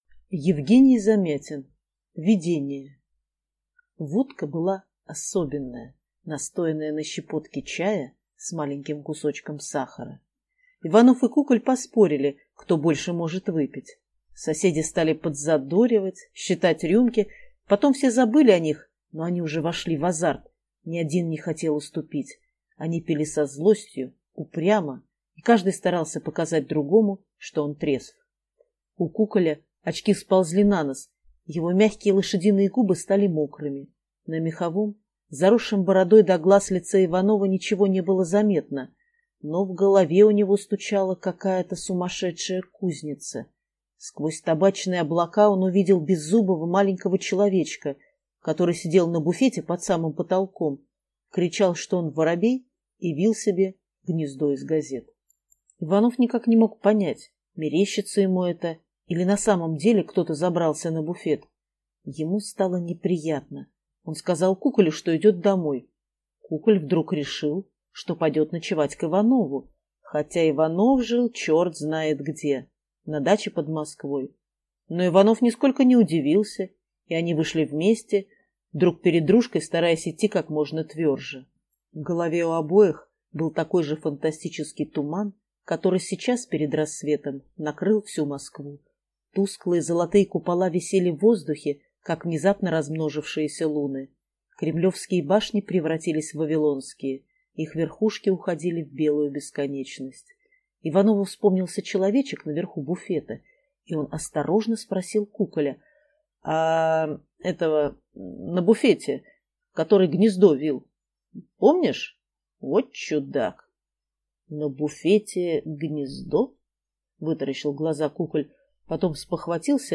Aудиокнига Видение